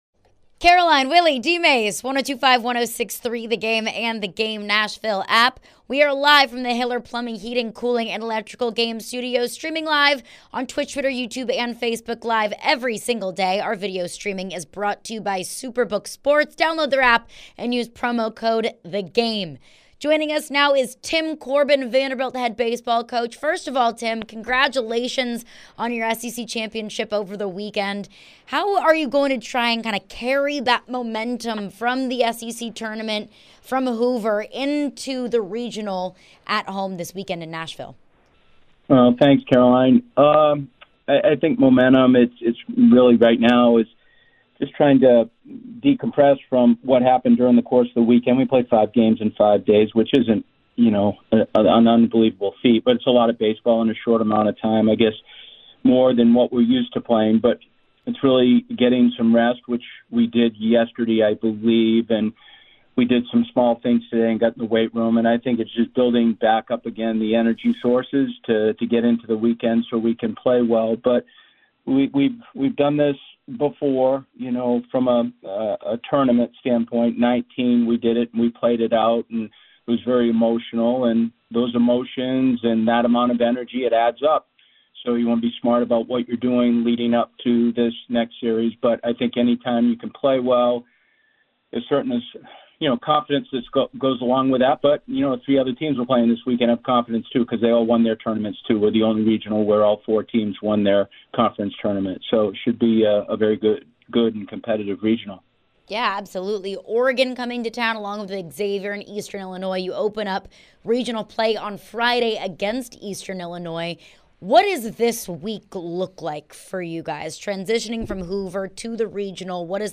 Tim Corbin Interview (5-30-23)